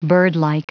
Prononciation du mot birdlike en anglais (fichier audio)
Prononciation du mot : birdlike